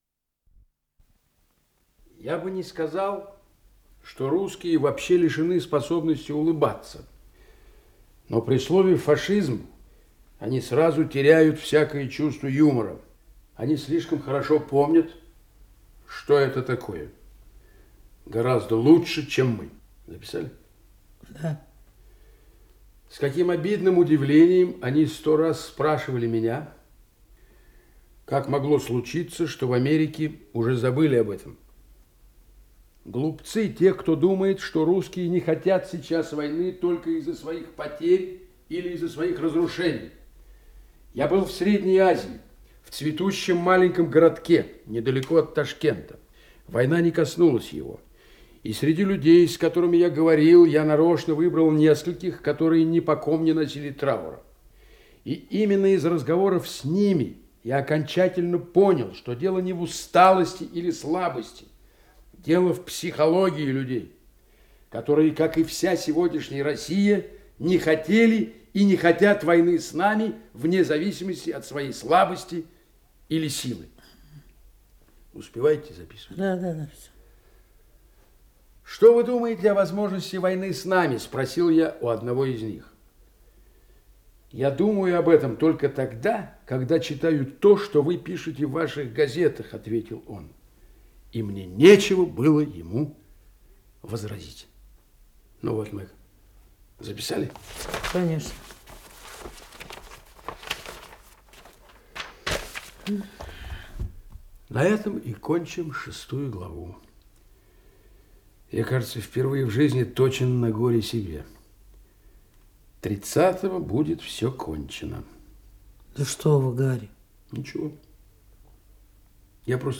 Исполнитель: Артисты московских театров
Радиоспектакль, часть 1-я